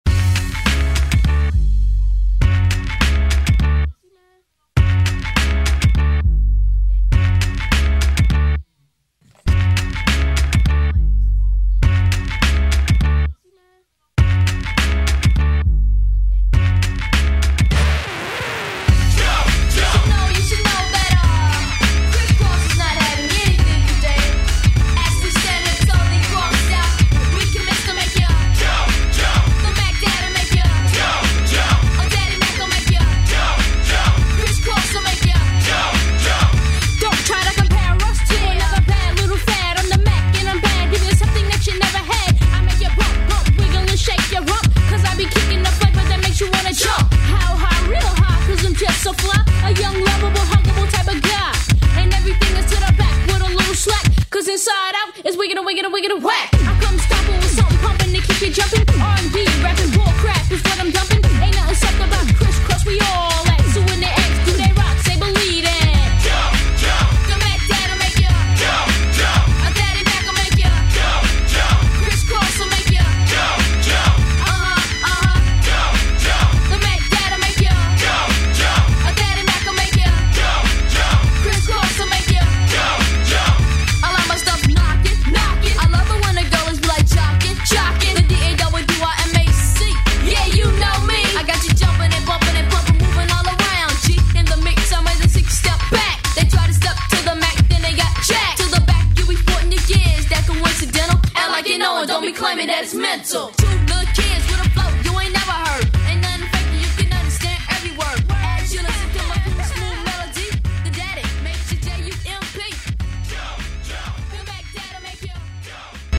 Radio Edit
Hip Hop Rap Music
Extended Intro Outro
Clean 102 bpm